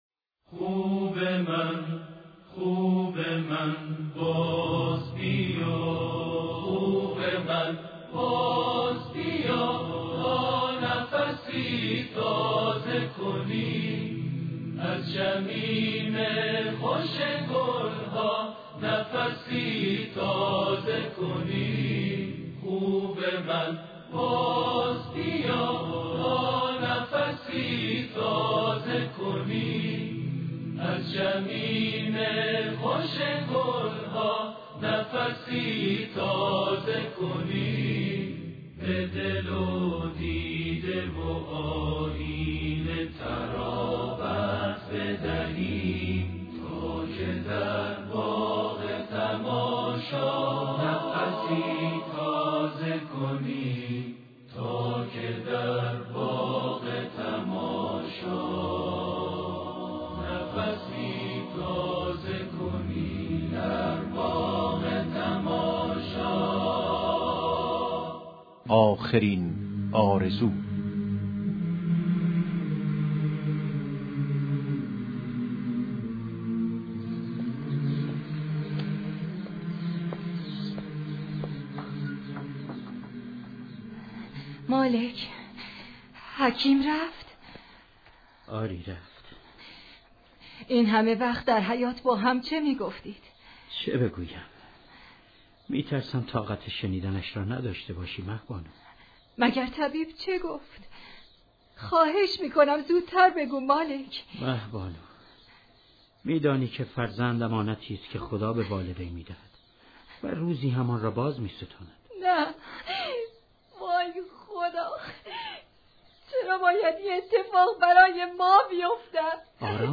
نمایش رادیویی | آخرین آرزو | شهرآرانیوز
نمایش رادیویی «آخرین آرزو» به مناسبت ایام شهادت حضرت زهرا(س) را در ادامه بشنوید.